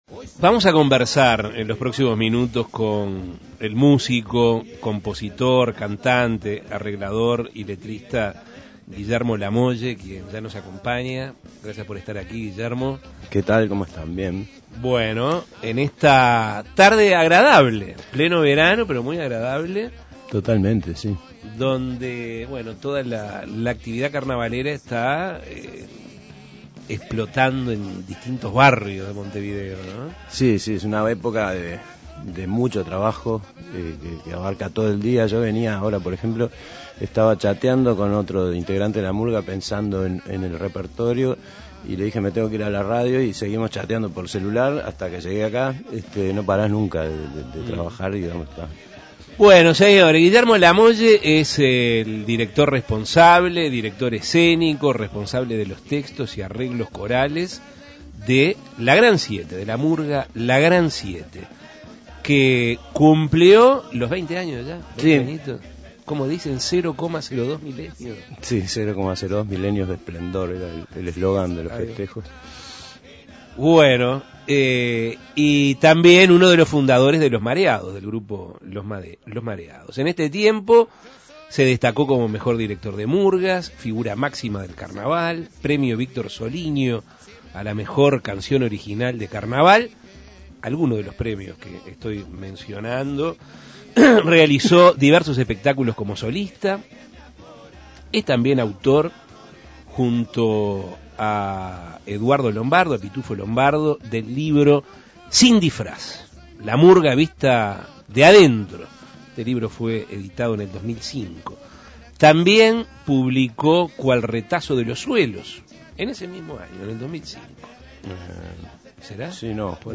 Entrevistas Al carnaval